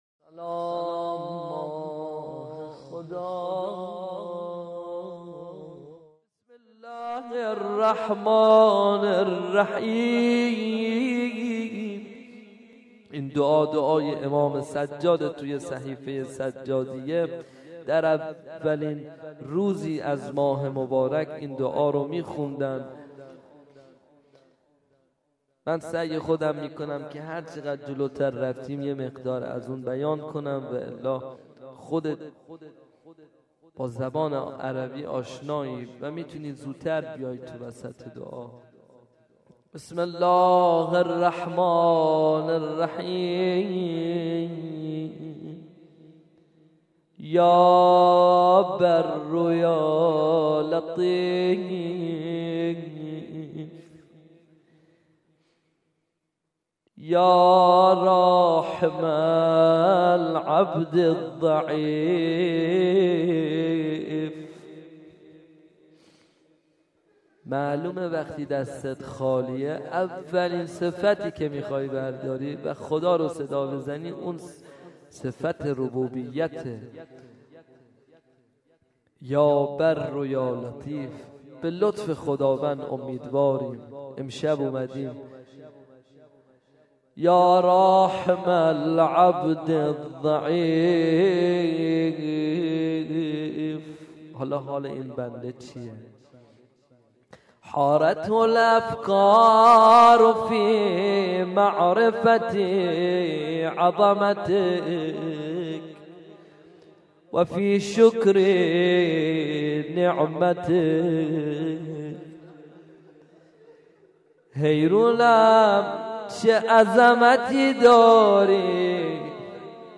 قرائت مناجات امام سجاد علیه السلام
در اولین روز از ماه رمضان- هیأت علی اکبر بحرین